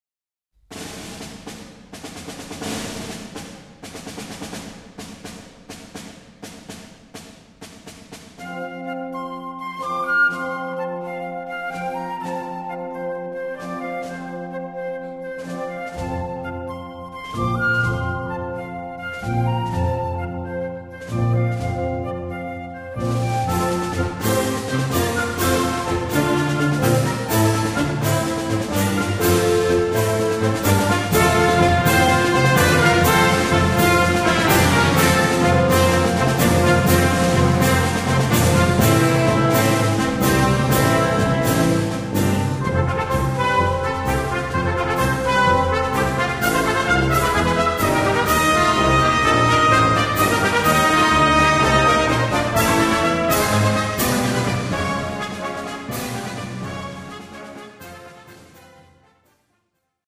Voicing: Concert Band - Blasorchester - Harmonie